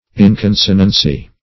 Search Result for " inconsonancy" : The Collaborative International Dictionary of English v.0.48: Inconsonance \In*con"so*nance\, Inconsonancy \In*con"so*nan*cy\, n. Lack of consonance or harmony of sound, action, or thought; disagreement.